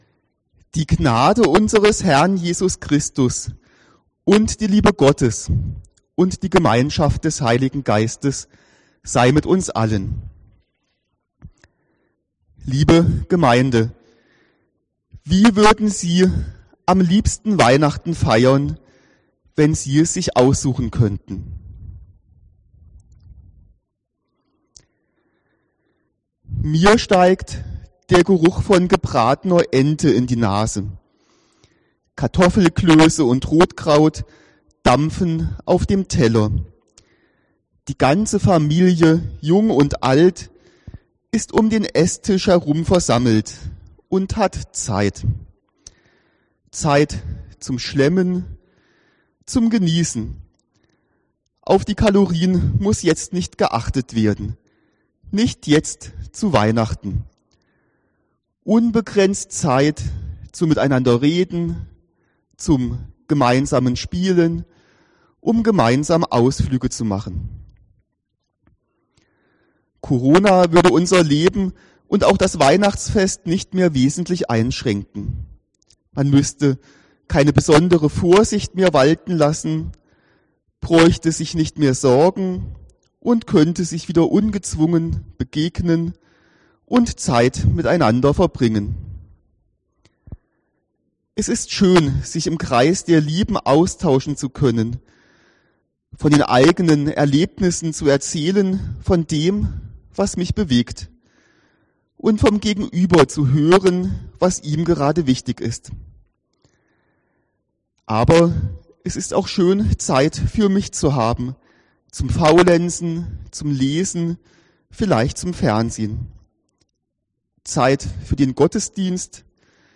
Evang. Ref. Kirchgemeinde Saas im Prättigau